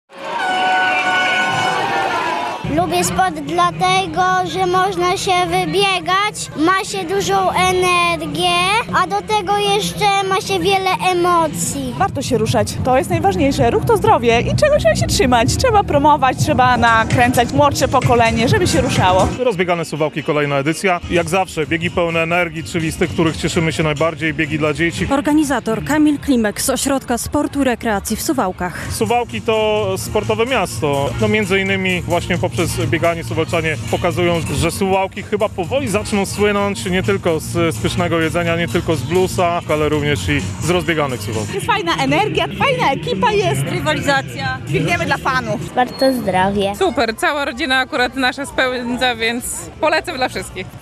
Rozbiegane Suwałki - relacja